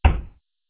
DoorClosed.wav